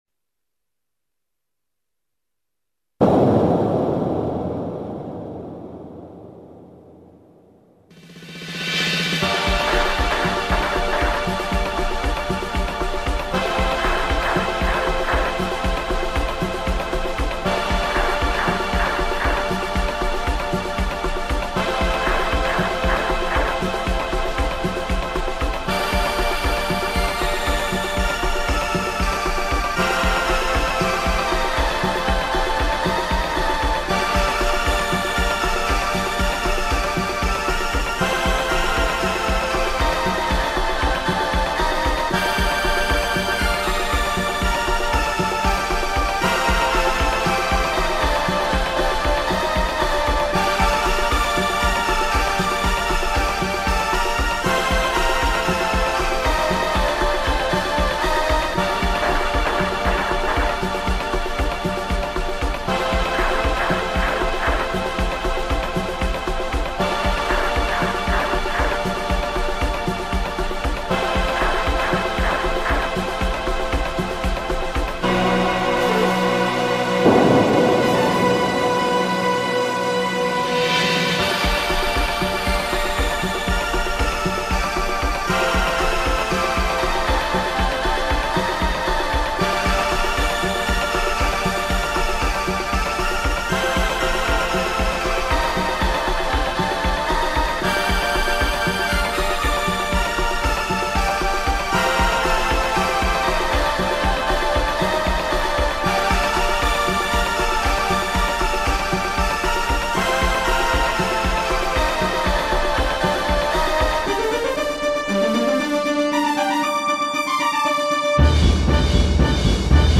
It was made on a Roland D-20 with my own samples.